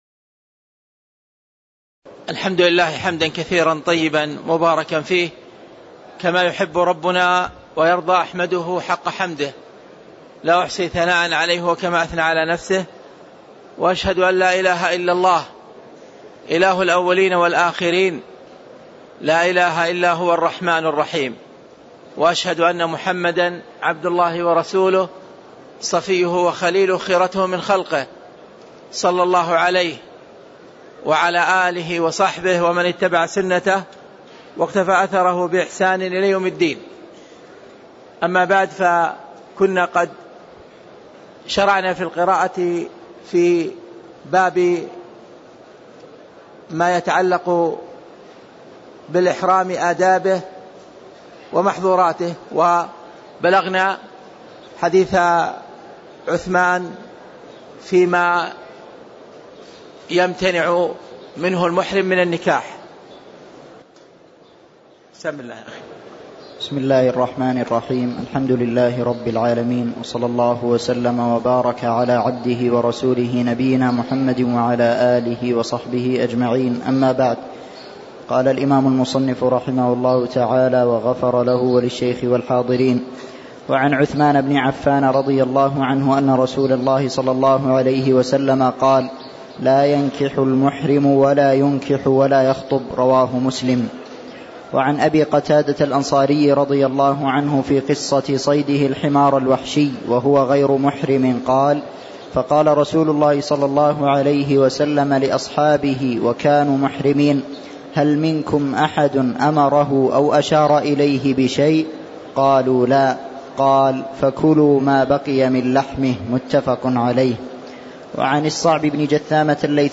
تاريخ النشر ١٠ ذو القعدة ١٤٣٦ هـ المكان: المسجد النبوي الشيخ